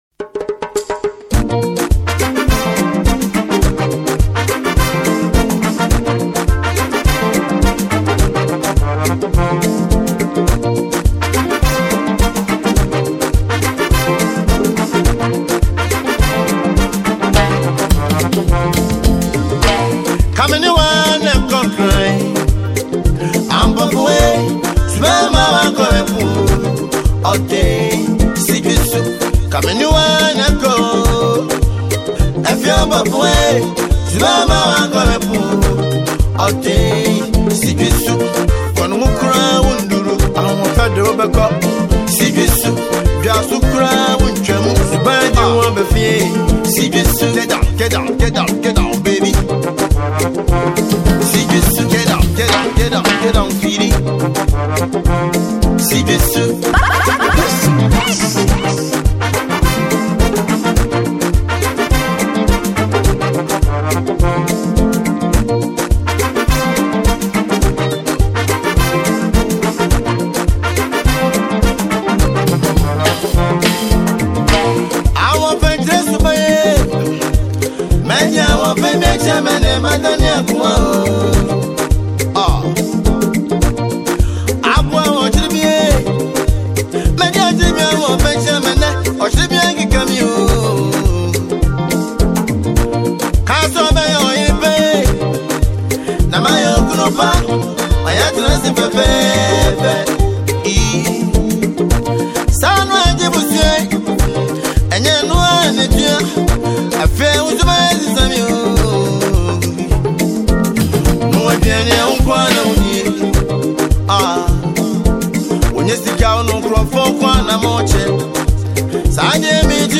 multi-talented highlife music singer